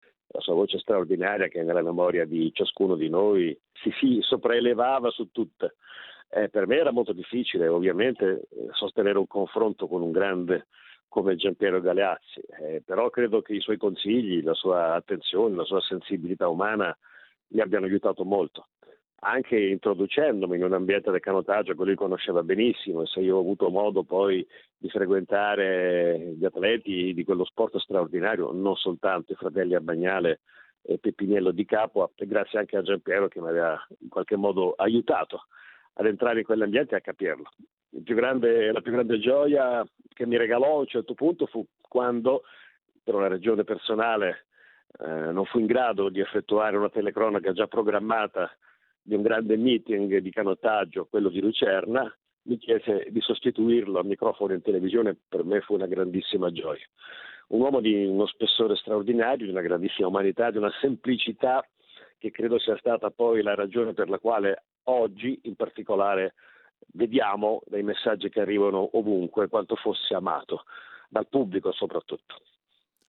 Abbiamo intervistato la voce di Tutto il Calcio Minuto per Minuto, il suo collega e amico Riccardo Cucchi: